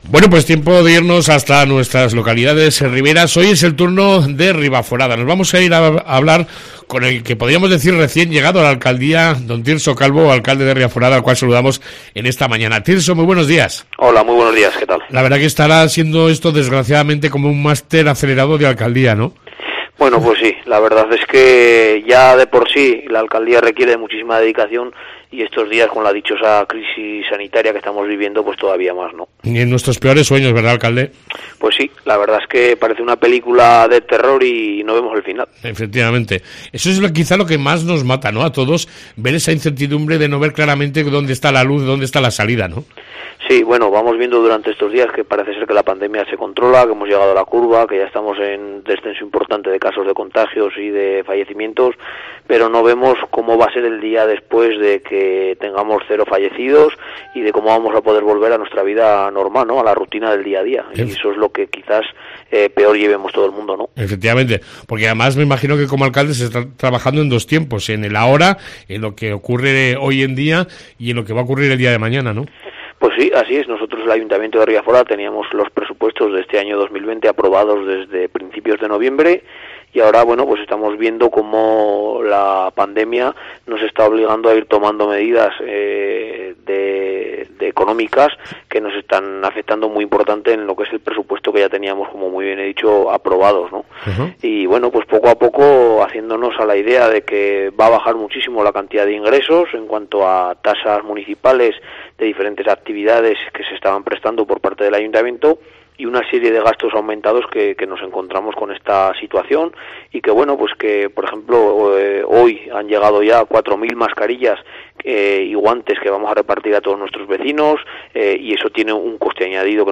AUDIO: Tirso Calvo, Alcalde de Ribaforada nos cuenta como están viviendo en Ribaforada esta crisis del Coronavirus